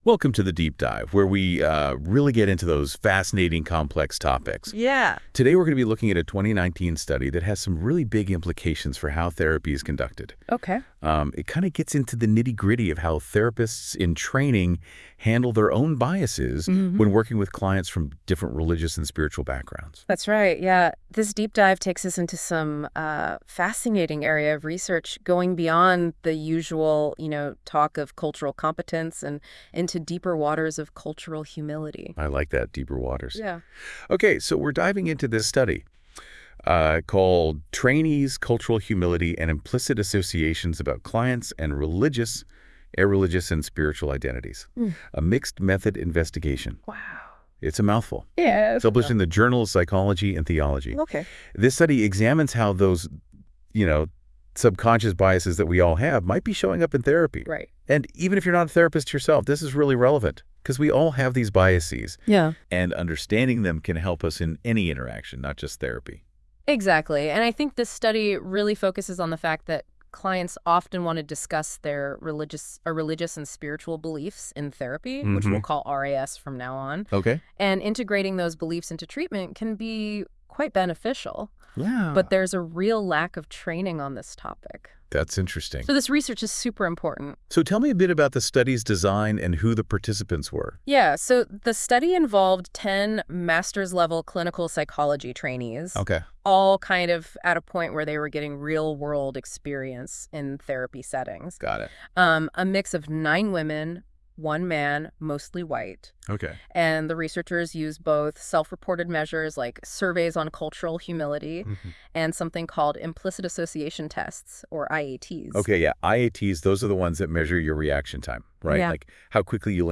This podcast was generated by Notebook LM and reviewed by our team, please listen with discretion.